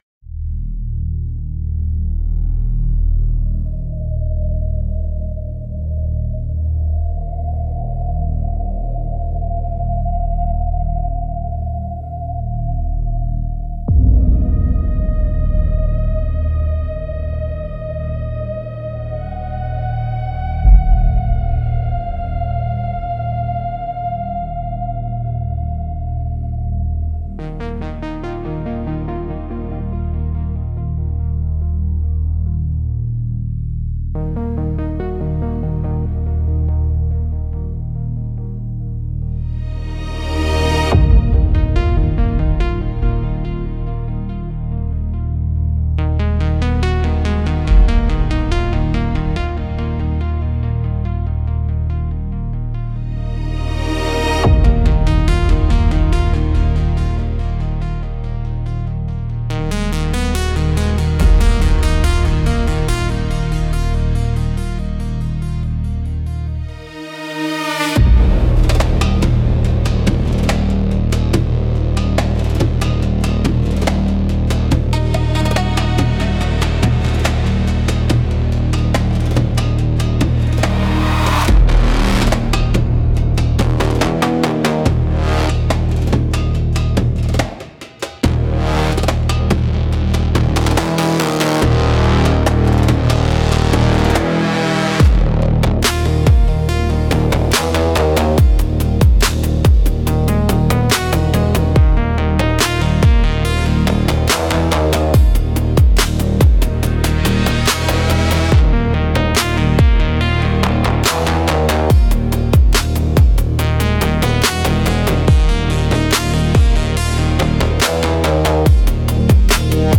Instrumental - Forgotten Signal from a Dead Channel 4.25